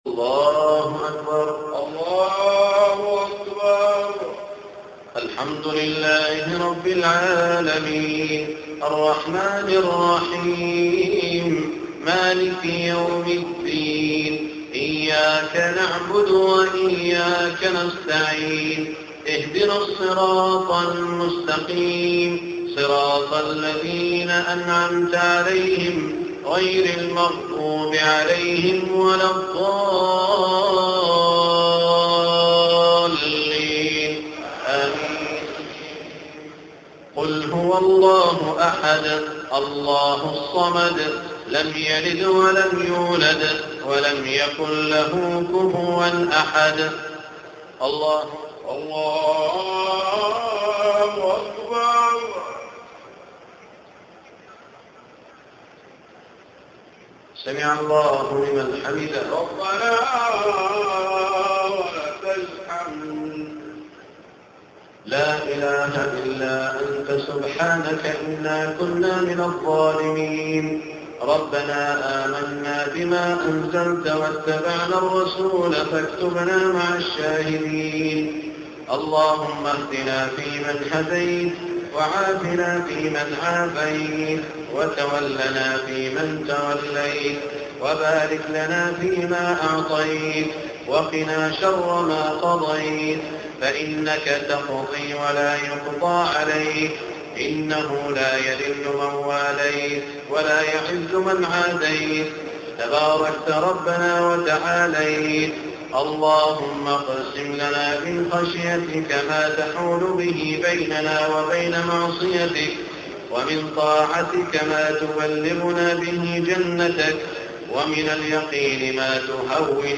دعاء القنوت للشيخ سعود الشريم 1425هـ > تراويح الحرم المكي عام 1425 🕋 > التراويح - تلاوات الحرمين